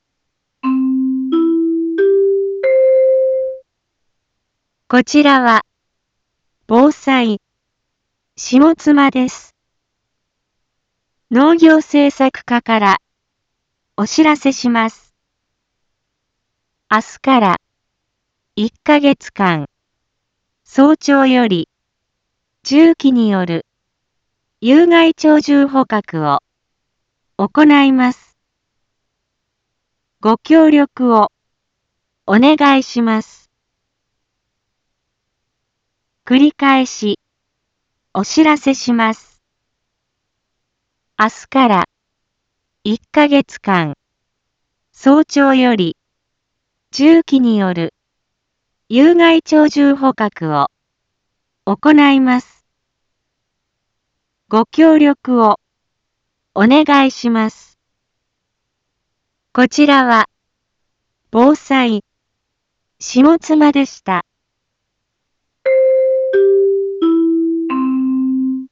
一般放送情報
Back Home 一般放送情報 音声放送 再生 一般放送情報 登録日時：2025-05-23 07:11:27 タイトル：有害鳥獣捕獲の実施についてのお知らせ インフォメーション：こちらは、ぼうさい、しもつまです。